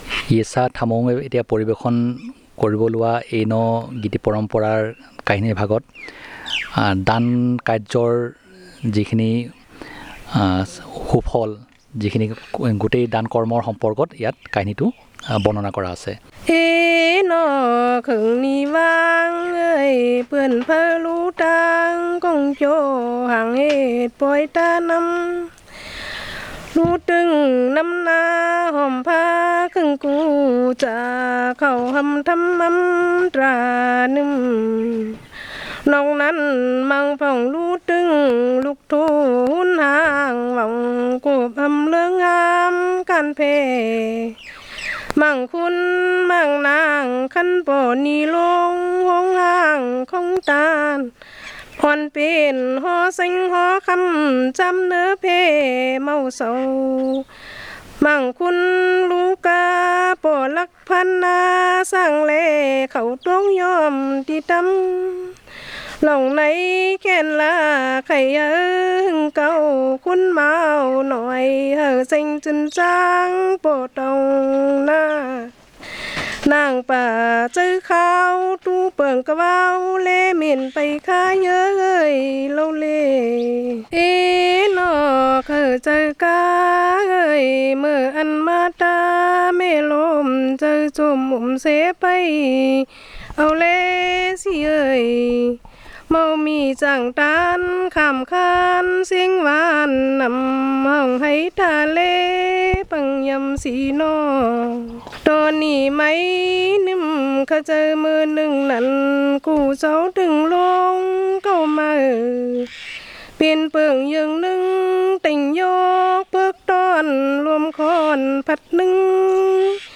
Performance of a cultural song